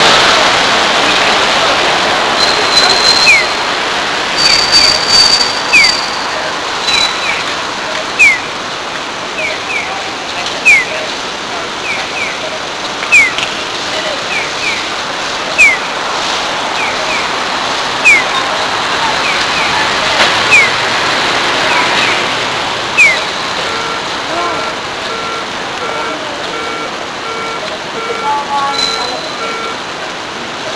最初のファイルで点滅音が分からないかもしれないので比較的点滅音が上手く録れた反対側のファイルも添付します。
音としては、京三の点滅音を長くしたような感じですね。
テンポは京三のに比べるとかなりゆっくりとしたテンポですね。